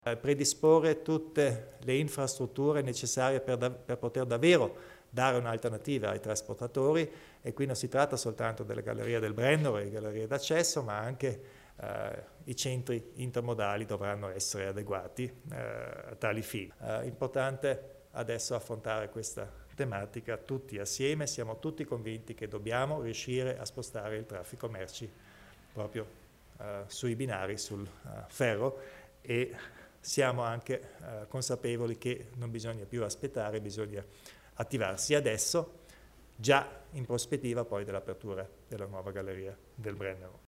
Il Presidente Kompatscher elenca le priorità sul tema traffico in ambito Euregio